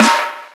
Snare (38).wav